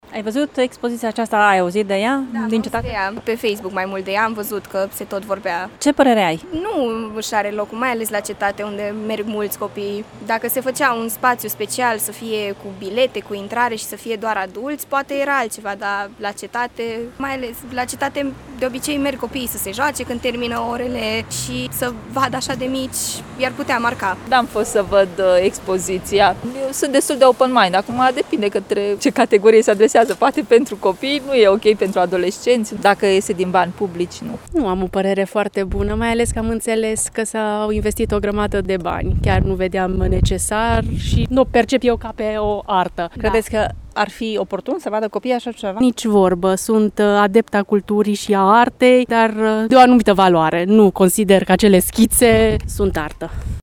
Unii târgumureșeni au aflat despre controversata expoziție finanțată din bani publici și spun că sunt îngrijorați de faptul că este permis accesul copiilor: